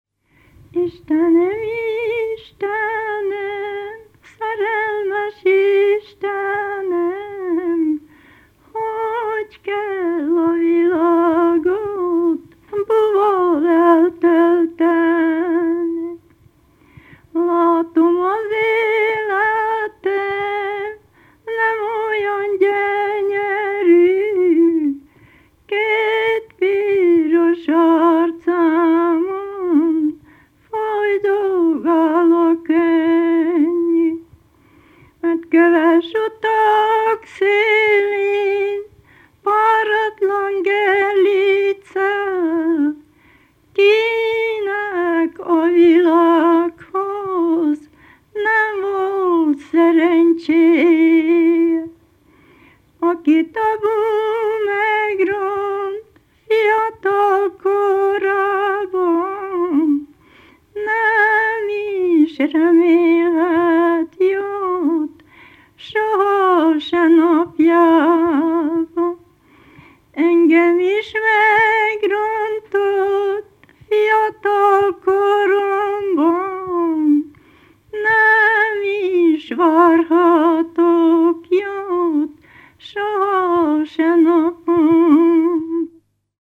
ének
keserves
Gyimesfelsőlok
Gyimes (Székelyföld, Erdély)